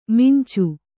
♪ mincu